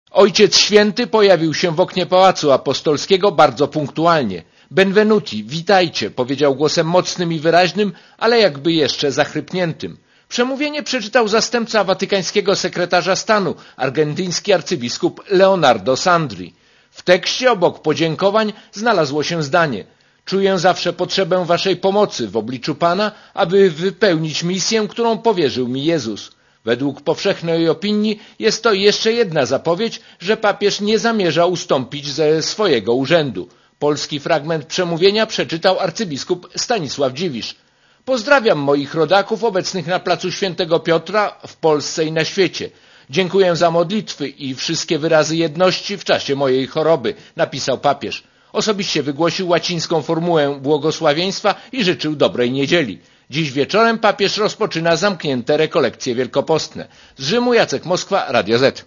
Papież osłabionym jeszcze głosem udzielił błogosławieństwa.